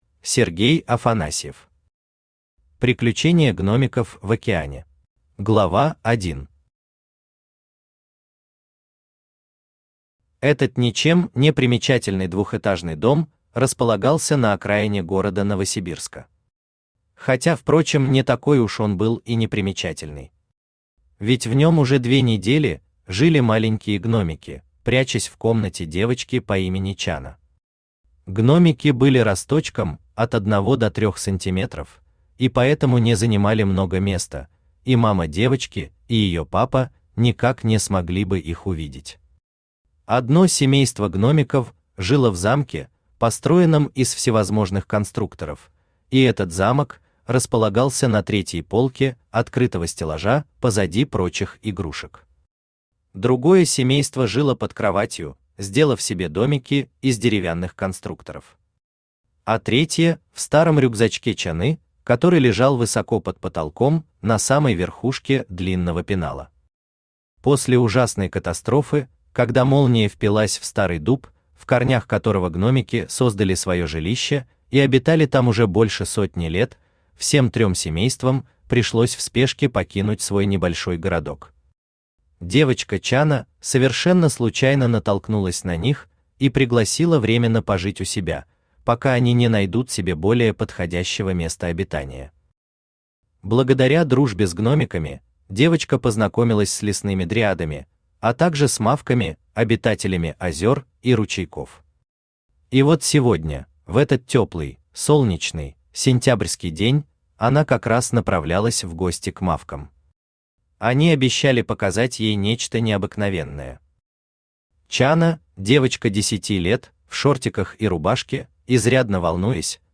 Аудиокнига Приключения гномиков в океане | Библиотека аудиокниг
Aудиокнига Приключения гномиков в океане Автор Сергей Афанасьев Читает аудиокнигу Авточтец ЛитРес.